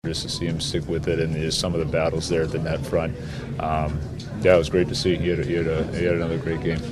Muse says Tristan Jarry was fantastic in goal, turning aside a load of prime scoring opportunities for Florida.